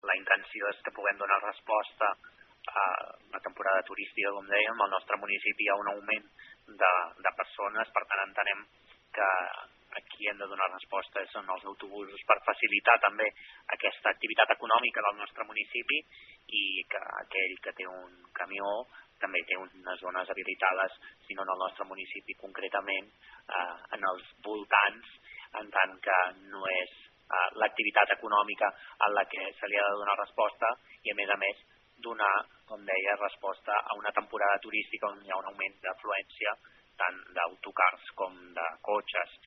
El regidor ha insistit en què la zona està destinada a donar resposta al turisme, i que per tant l’accés es limita només a cotxes i autocars, recordant que els camions ja tenen disponibles altres zones d’estacionament al municipi.